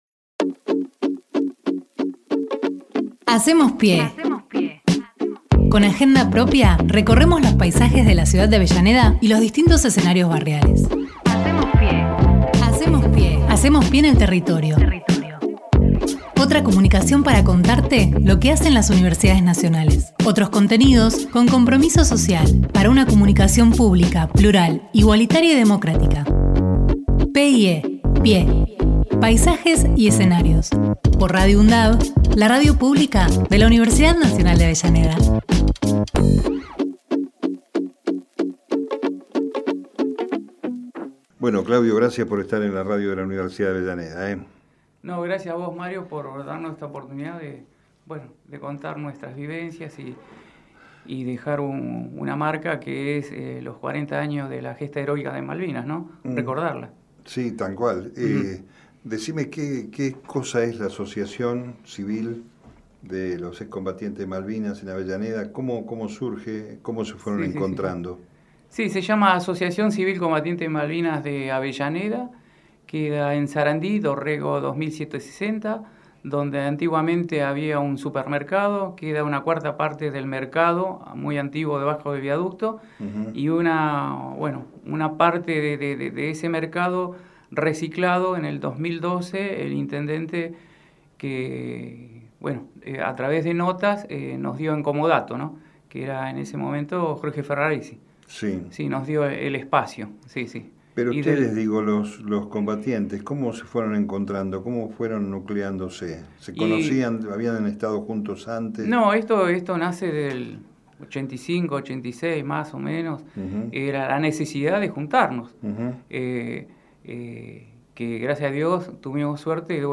Compartimos la entrevista emitida en Hacemos PyE